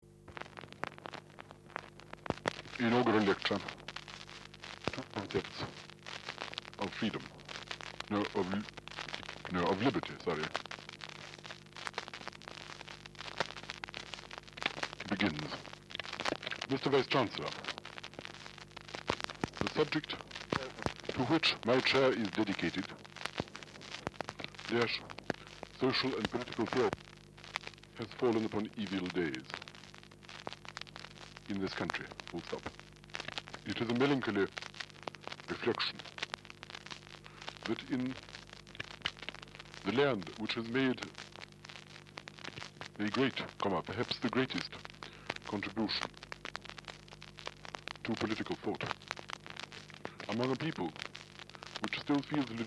I’ve chosen just one more to play now, since it preserves the moment in 1958 when Berlin was dictating for his secretary his most important essay, his inaugural lecture as Professor of Social and Political Theory at Oxford, ‘Two Concepts of Liberty’.